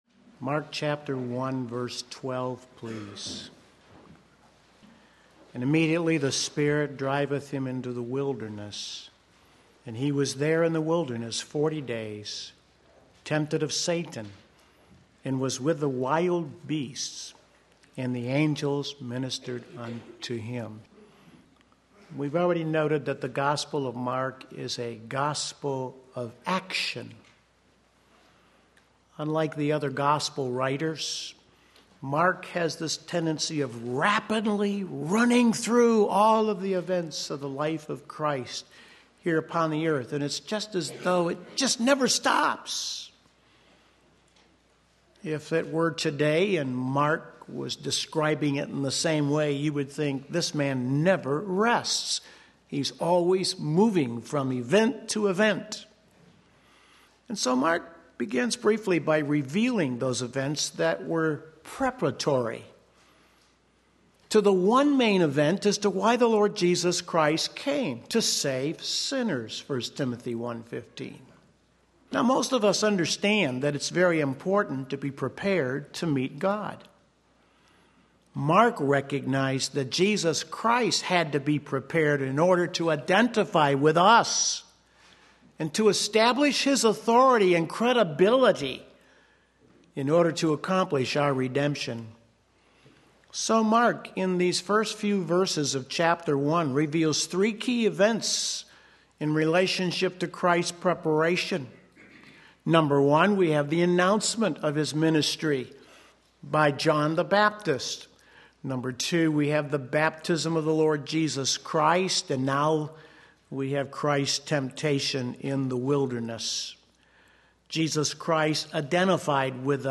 Sermon Link
The Temptation of Jesus Christ Mark 1:12-13 Sunday Morning Service